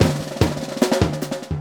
LOOP39SD06-R.wav